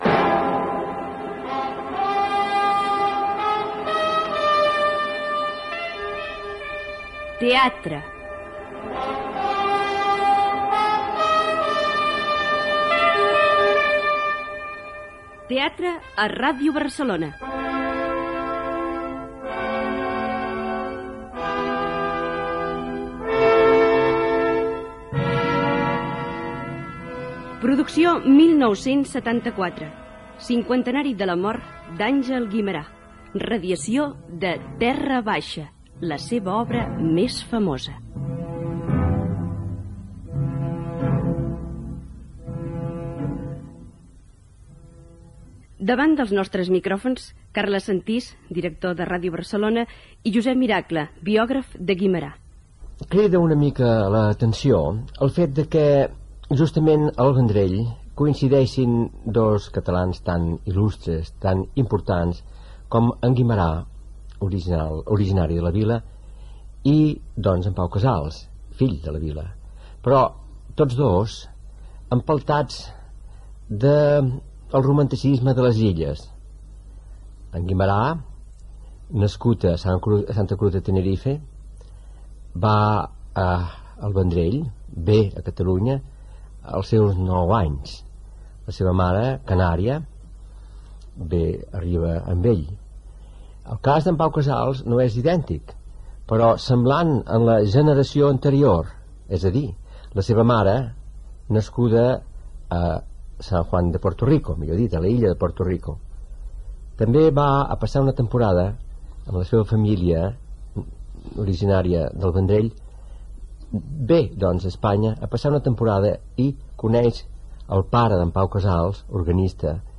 Careta del programa
Escena inicial de l'adaptació radiofònica de 'Terra Baixa', d'Àngel Guimerà. Gènere radiofònic Ficció